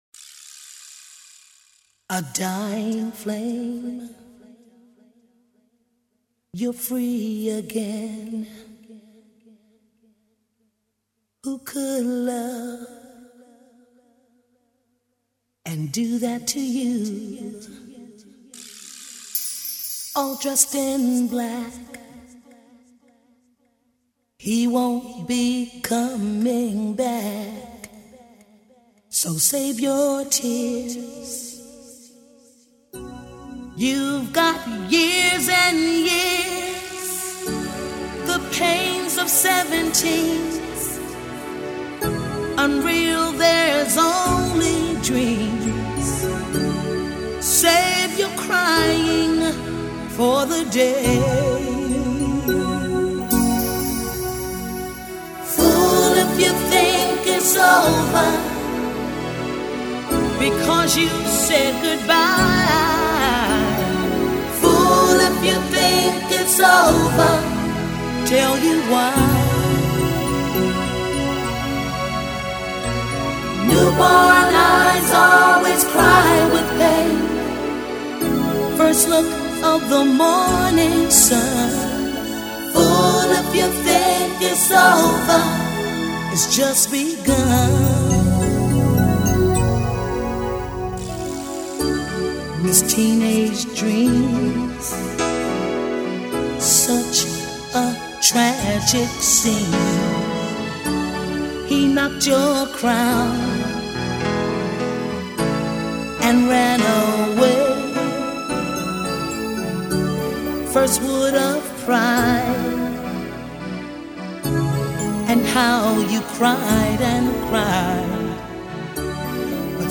is an American R&B and dance music singer.